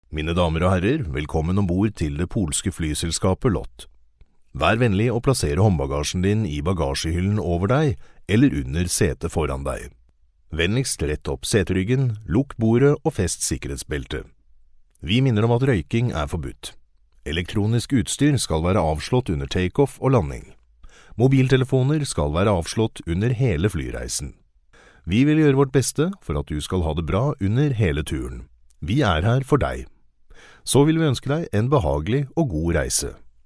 Professioneller norwegischer Sprecher für TV/Rundfunk/Industrie.
norwegischer Sprecher
Sprechprobe: Werbung (Muttersprache):
norwegian voice over